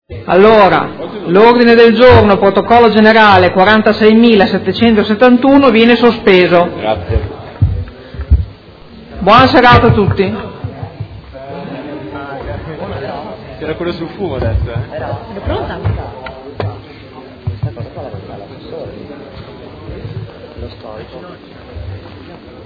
Seduta del 12/05/2016. Sospende Mozione presentata dal Gruppo Movimento Cinque Stelle avente per oggetto: Spese quotidiani presso edicole. Chiusura lavori